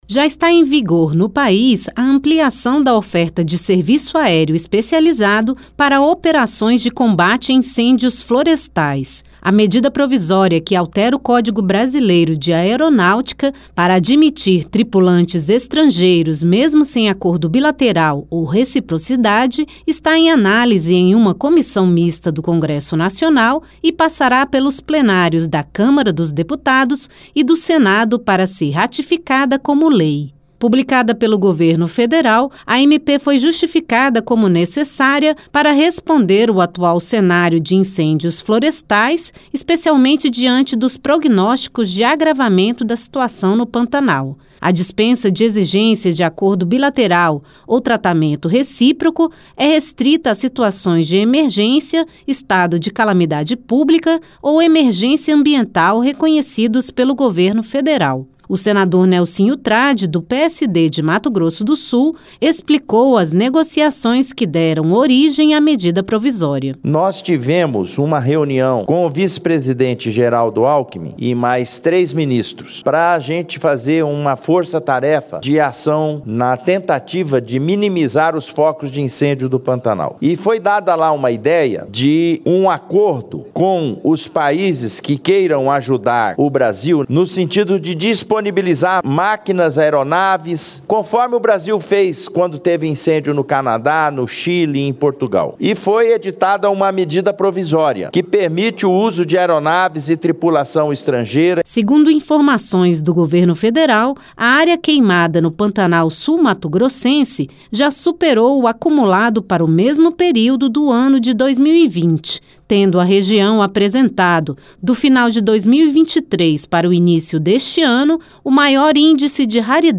O senador Nelsinho Trad (PSD-MS) explicou a origem da MP, que será votada no Congresso para ser ratificada como lei.